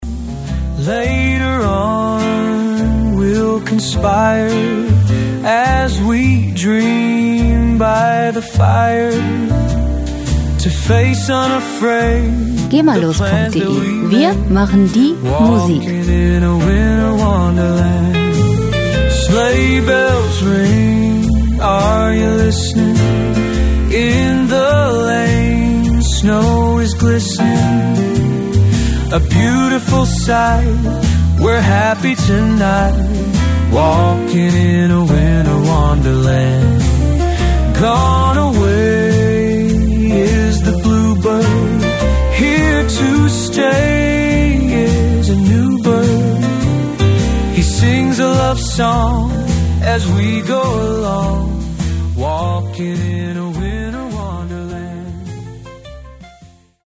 Christmas Jazz & Christmas Swing
Musikstil: Christmas Swing
Tempo: 104 bpm
Tonart: F-Dur
Charakter: gelassen, locker
Instrumentierung: Swing Orchester, Gesang